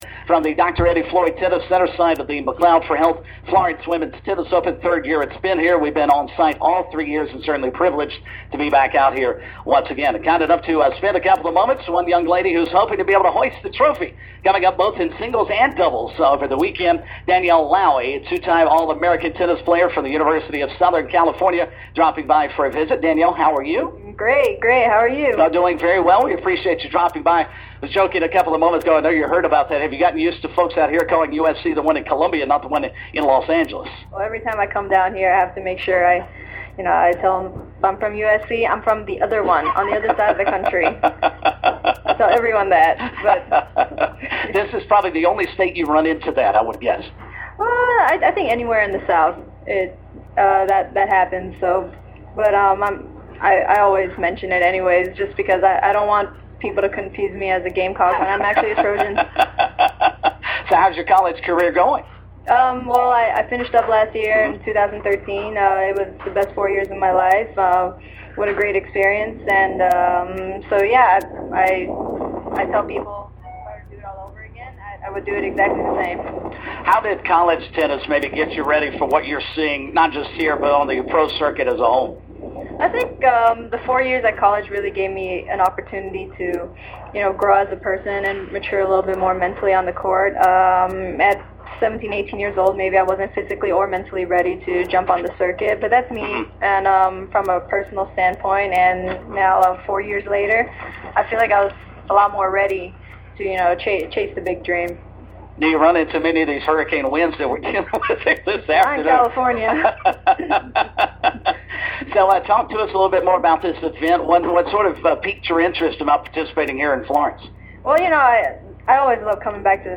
she was interviewed on 100.1 FM The Fan